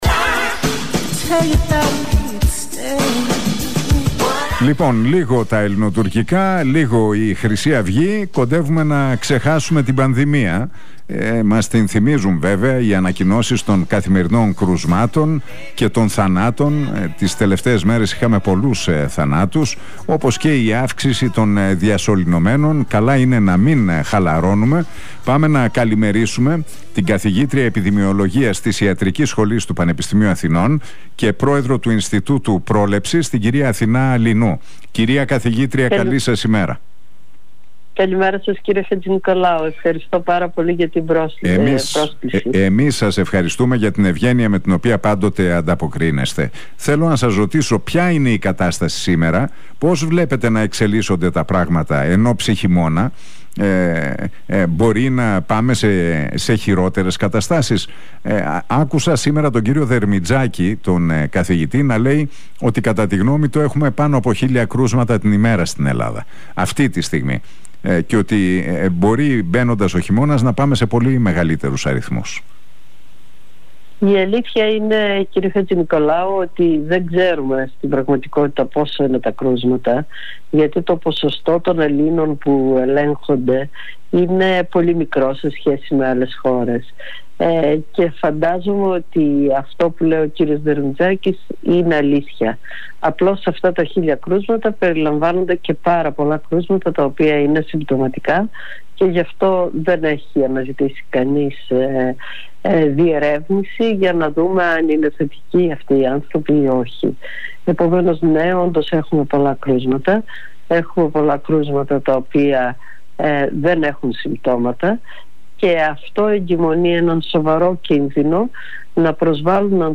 Μιλώντας στον Realfm 97,8 και την εκπομπή του Νίκου Χατζηνικολάου σημείωσε ότι “υπάρχουν μέτρα που πρέπει να λάβει η πολιτεία και μέτρα που πρέπει να λάβουν οι πολίτες.